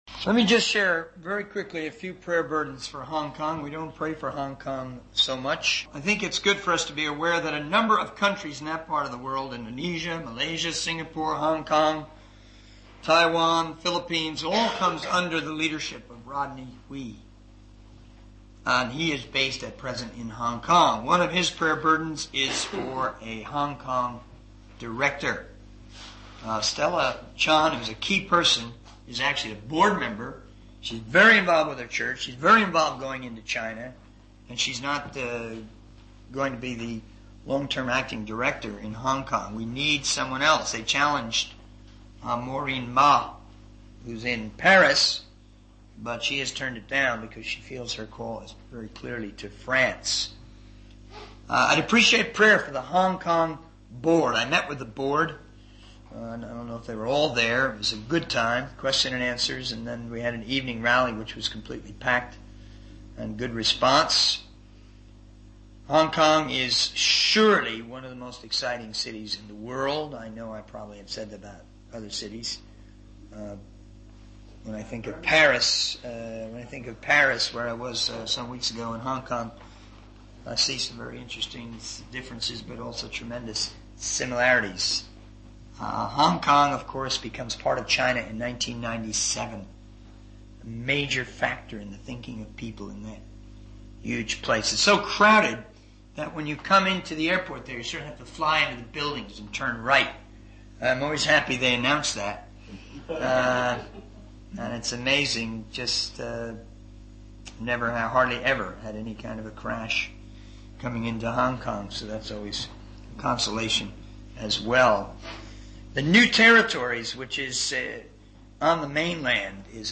In this sermon transcript, the speaker shares about his recent experiences in Singapore, where he met influential individuals involved in the missionary movement. He emphasizes the need for more missionary books to be made available to people.